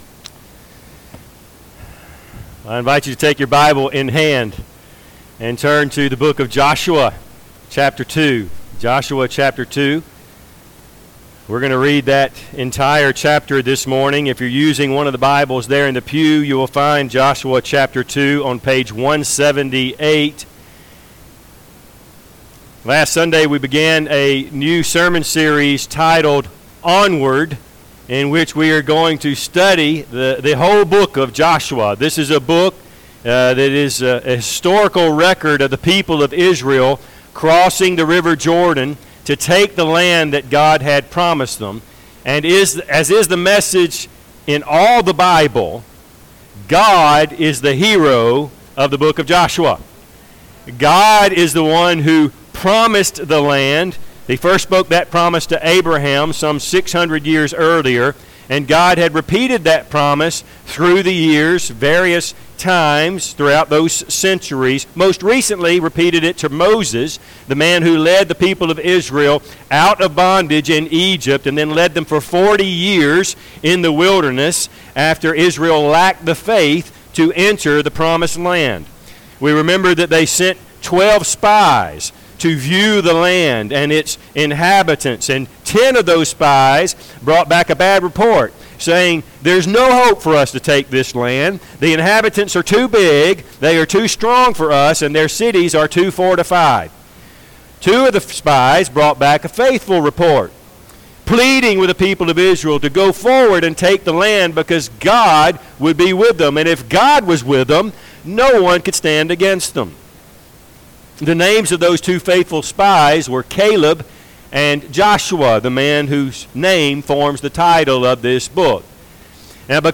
Joshua 2:1-24 Service Type: Sunday AM Bible Text